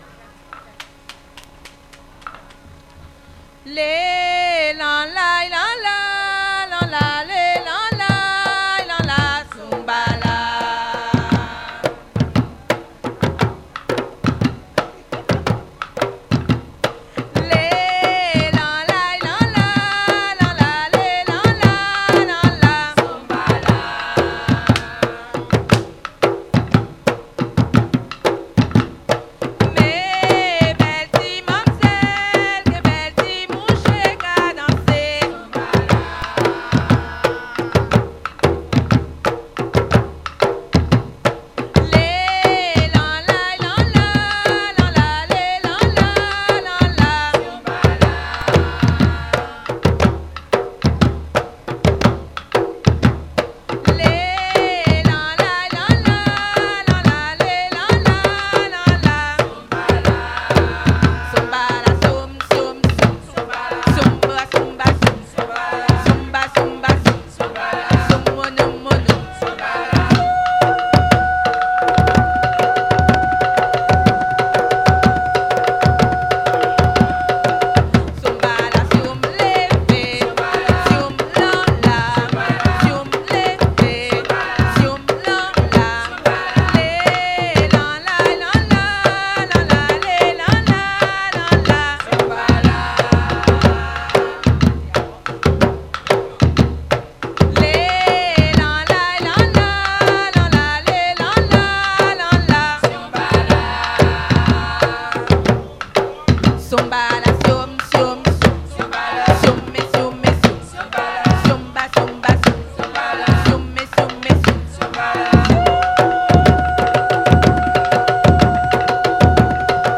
Saint-Georges-de-l'Oyapoc
danse : grajévals (créole)
Pièce musicale inédite